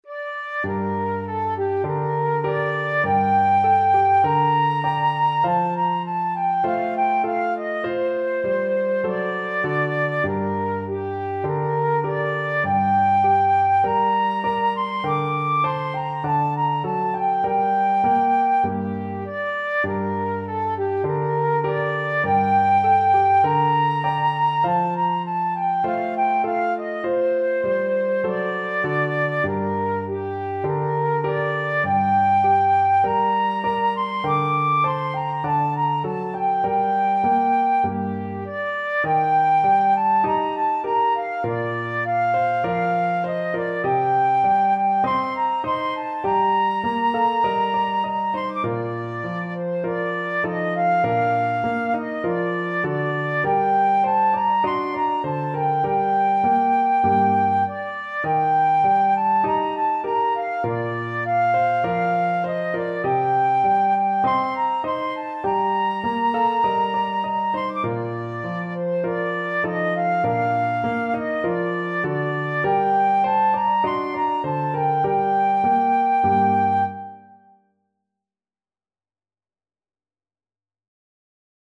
2/2 (View more 2/2 Music)
Steady two in a bar = c.50